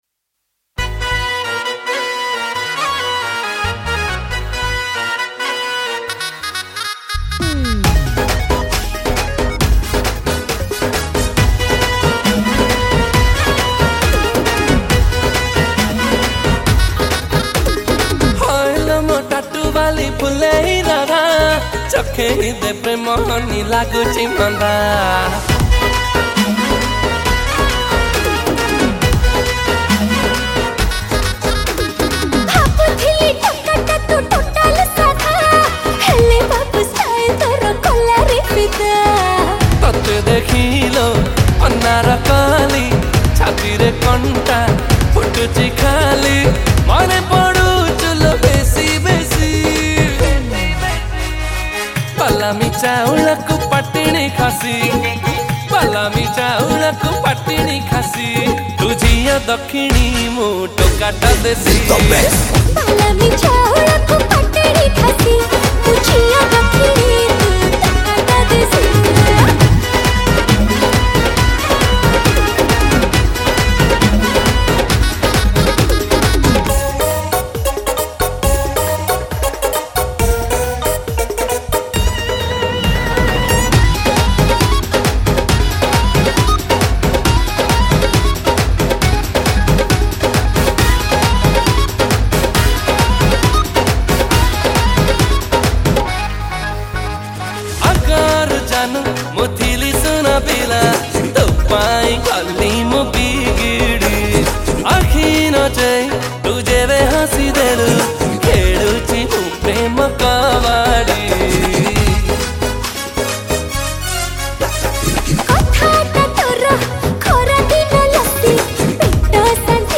Single Odia Album Song 2025